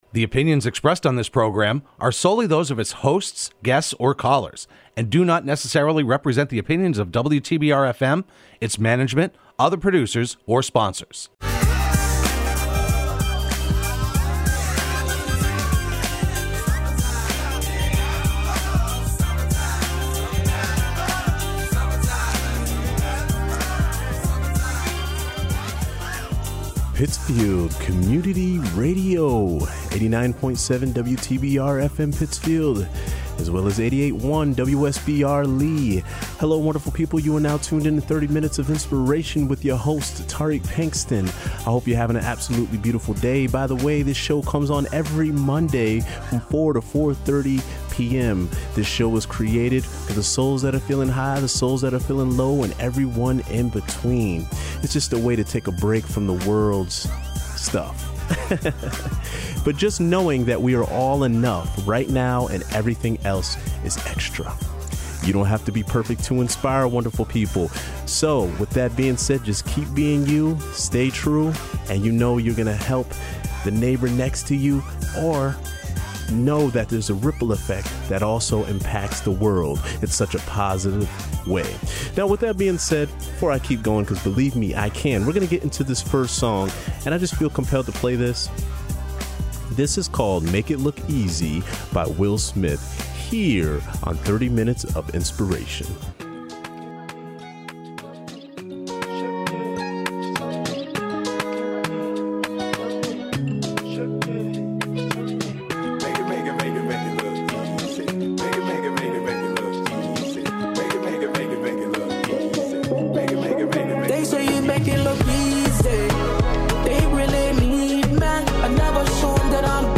broadcast live every Monday afternoon at 4pm on WTBR